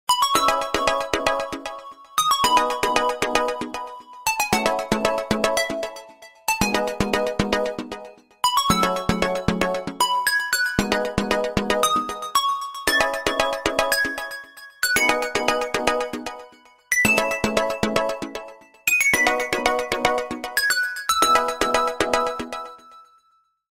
Рингтон Писклявый Звук Вызова
• Качество: 320 kbps, Stereo